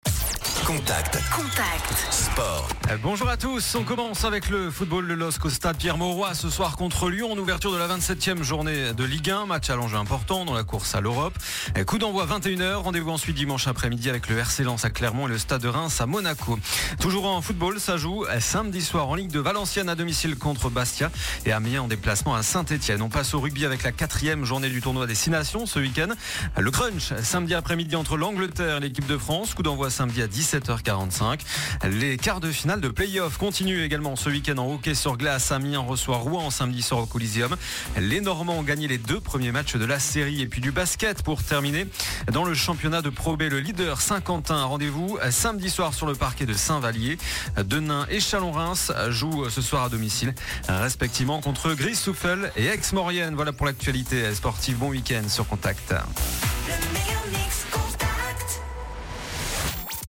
Le journal des sports du vendredi 10 mars